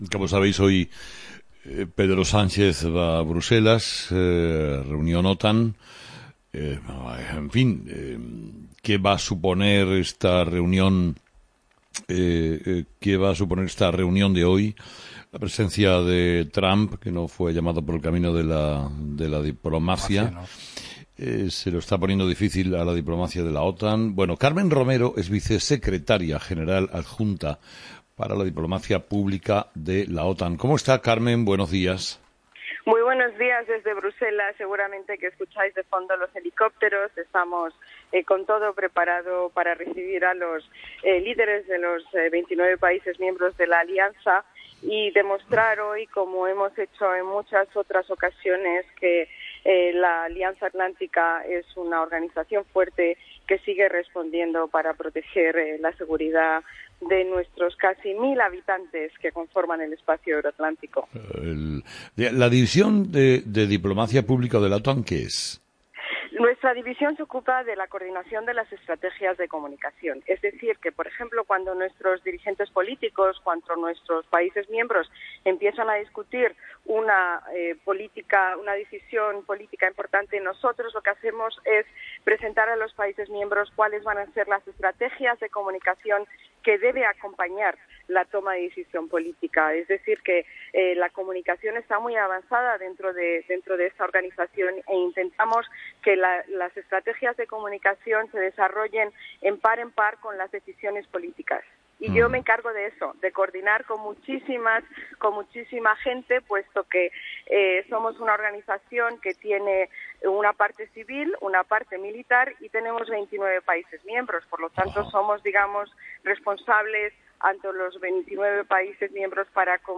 Entrevista a Carmen Romero